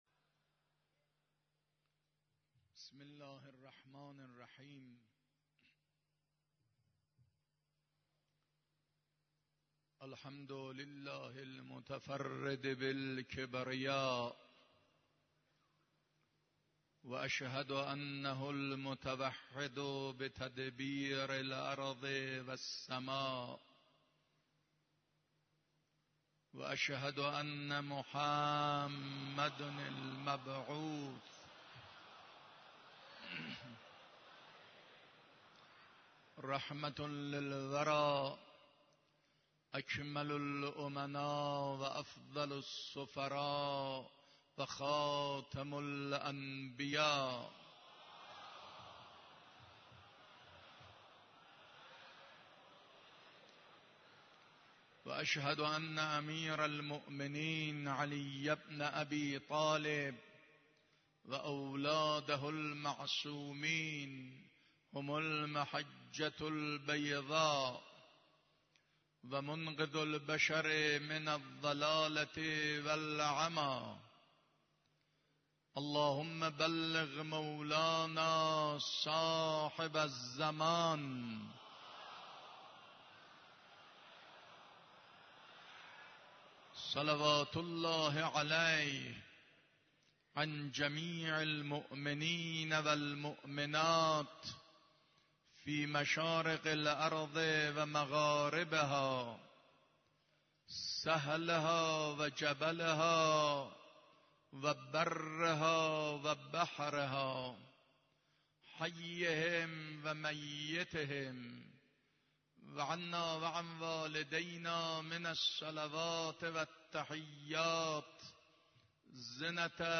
خطبه اول نماز جمعه 6 تیرماه 93.mp3
خطبه-اول-نماز-جمعه-6-تیرماه-93.mp3